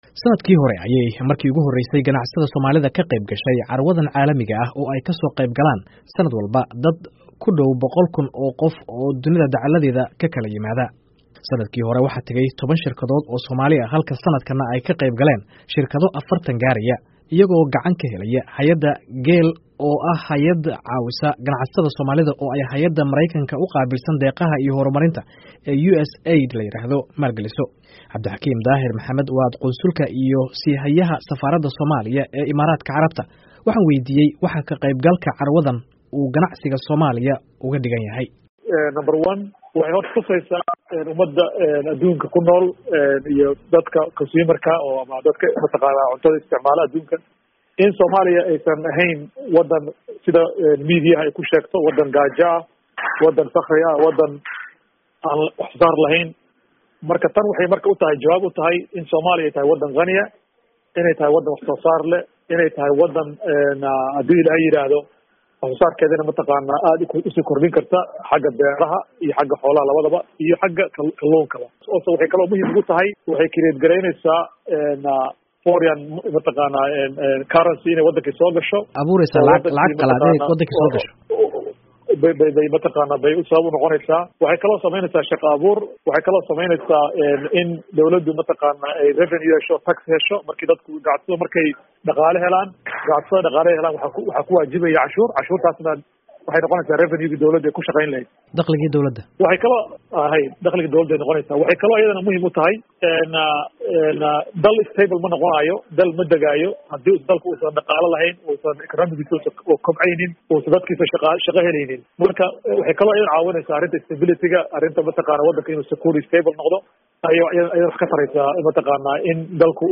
Wariyeheenna